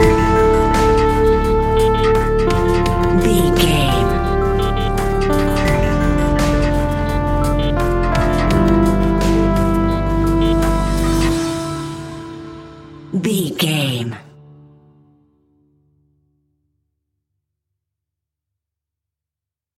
Aeolian/Minor
D
scary
tension
ominous
dark
haunting
eerie
piano
strings
drums
synthesiser
ticking
electronic music
Horror Pads